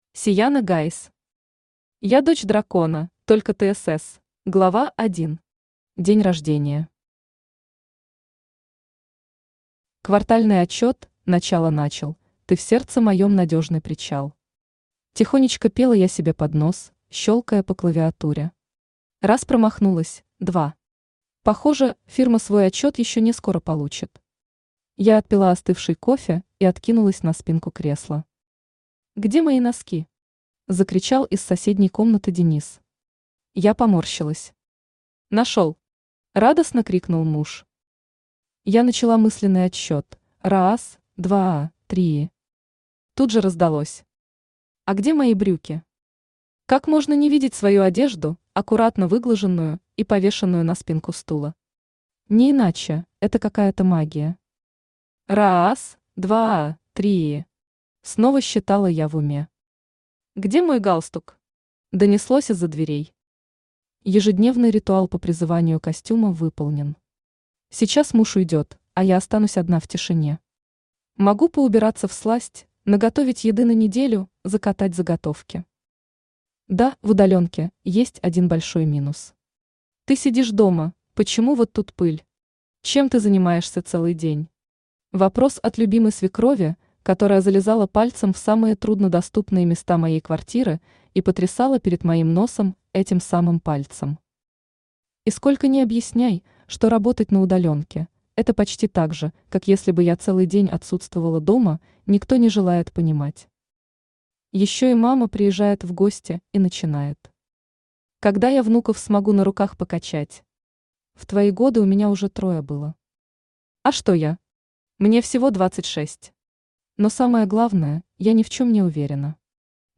Аудиокнига Я дочь дракона, только тсс!
Автор Сияна Гайс Читает аудиокнигу Авточтец ЛитРес.